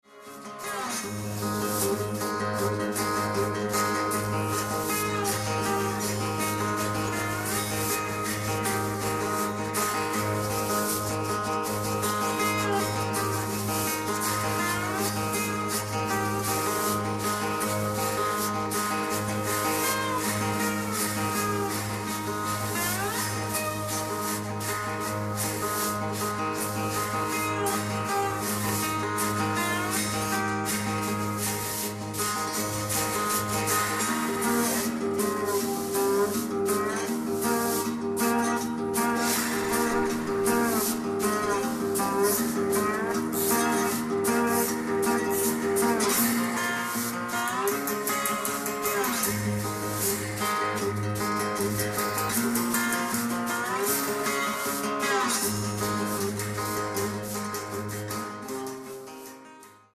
guitars
percussions, items